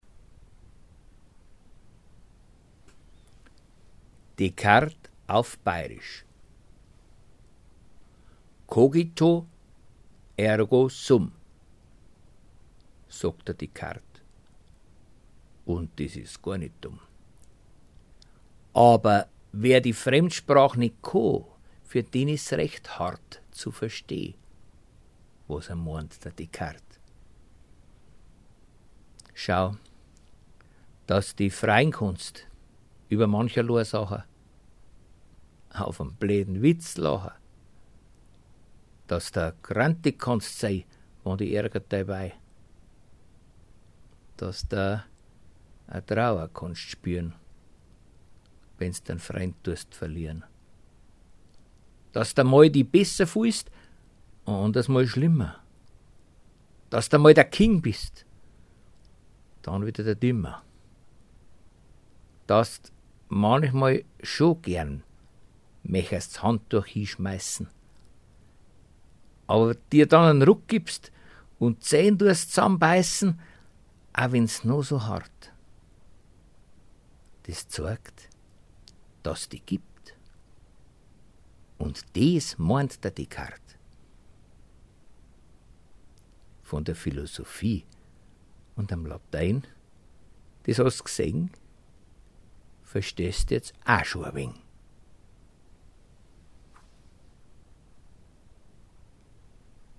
ste-006_descartes_auf_bayrisch.mp3